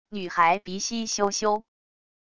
女孩鼻息咻咻wav音频